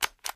DZ_Click_Sound.mp3